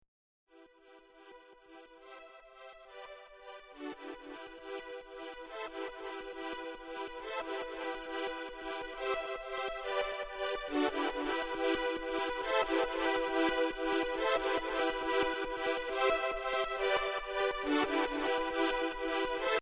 Так он же, вроде, на JP 8080 Я нашел похожий пэд случайно в сторонних пресетах